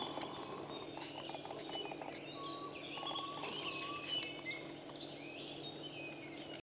Chululú Cabeza Rojiza (Grallaria albigula)
Nombre en inglés: White-throated Antpitta
Fase de la vida: Adulto
Localidad o área protegida: Parque Nacional Calilegua
Condición: Silvestre
Certeza: Vocalización Grabada